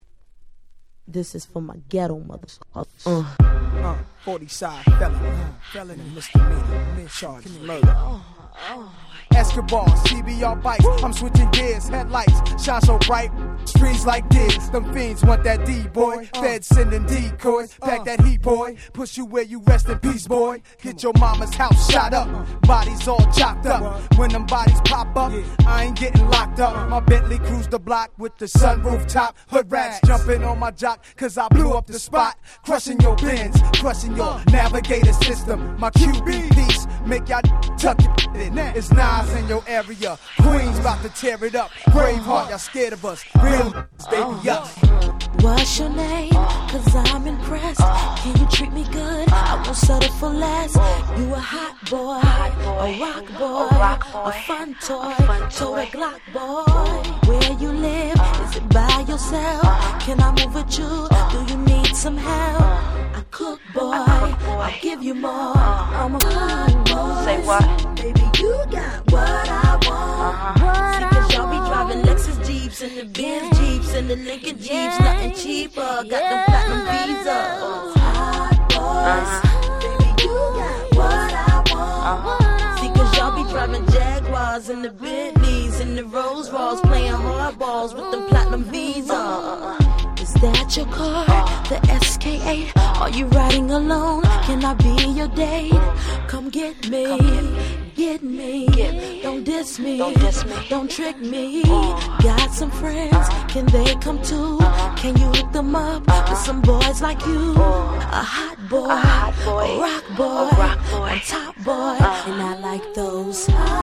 99' Super Hit Hip Hop !!
客演陣も豪華なLate 90's Hip Hop Classicsです。